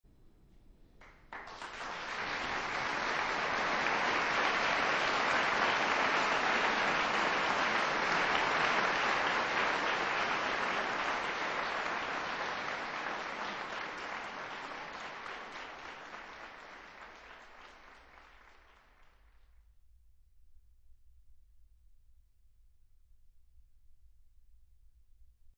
Genre-Stil-Form: Wiegenlied ; weltlich
Chorgattung: SMAA  (4 Kinderchor ODER Frauenchor Stimmen )
Tonart(en): frei